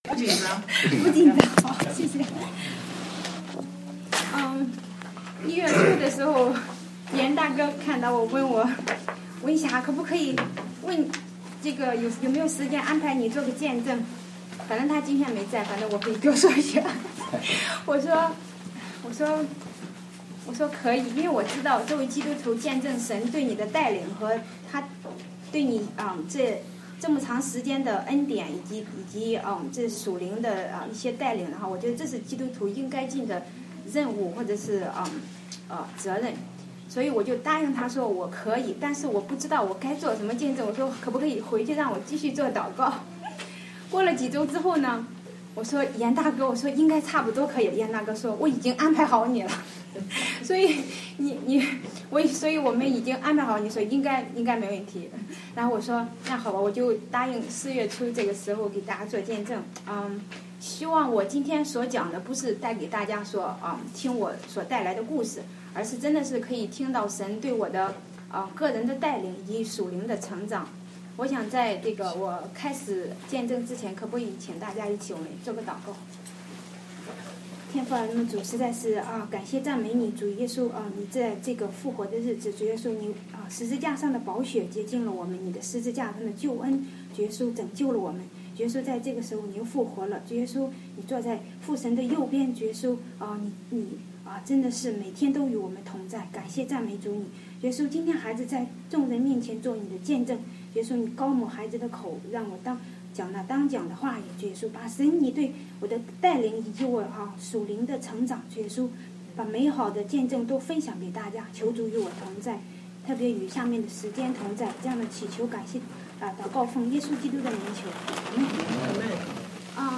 見證分享